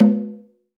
AFRO.TAMB3-S.WAV